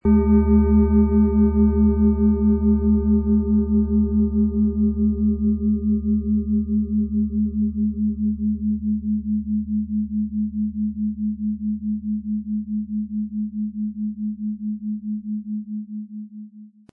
Es ist eine von Hand getriebene Klangschale, aus einer traditionellen Manufaktur.
• Mittlerer Ton: Uranus
Unter dem Artikel-Bild finden Sie den Original-Klang dieser Schale im Audio-Player - Jetzt reinhören.
PlanetentöneSaturn & Uranus
MaterialBronze